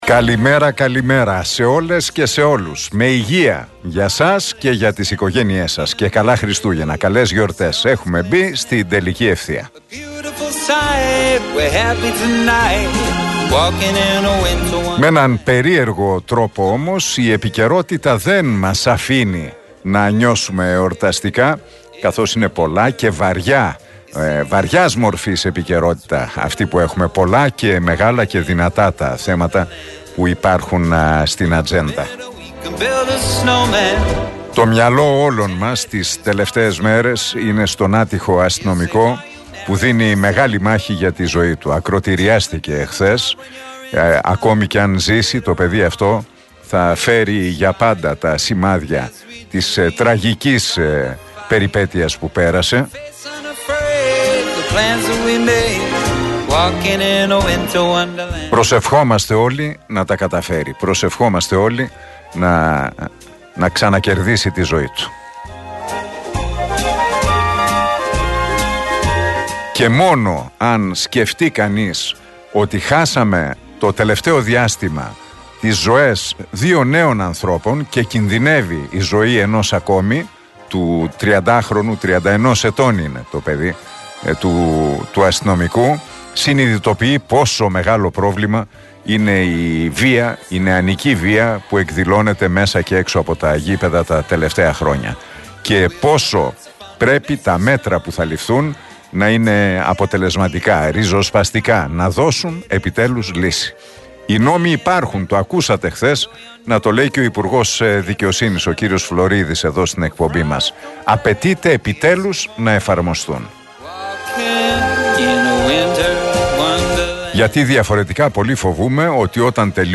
Ακούστε το σχόλιο του Νίκου Χατζηνικολάου στον RealFm 97,8, την Τετάρτη 13 Δεκεμβρίου 2023.